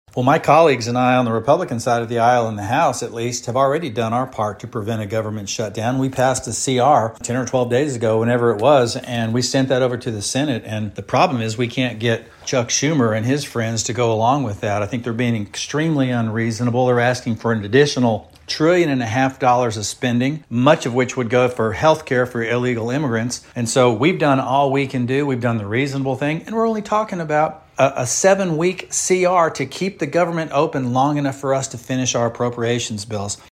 The federal government has entered a shutdown as the U.S. Senate has been unable to pass the House’s clean 7-week spending bill. Arkansas’ First District Congressman Rick Crawford weighs in on the shutdown and what we can expect.